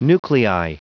Prononciation du mot nuclei en anglais (fichier audio)
Prononciation du mot : nuclei